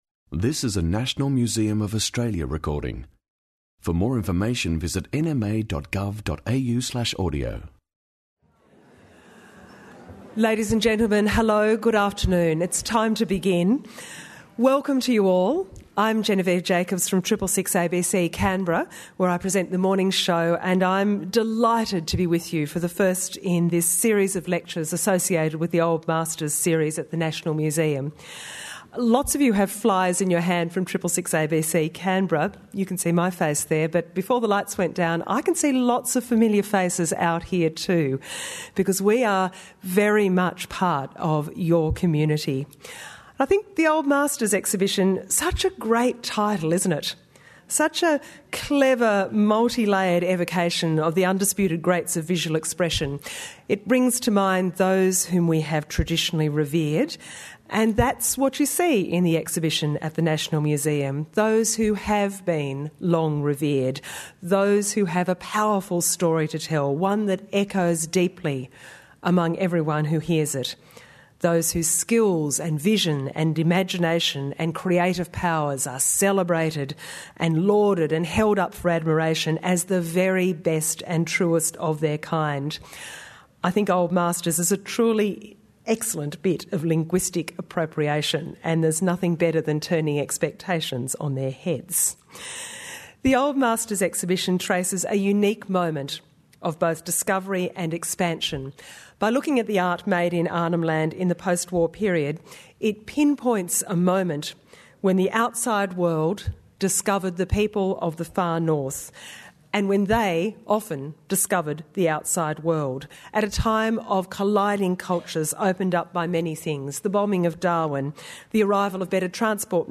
Old Masters lecture series 05 Mar 2014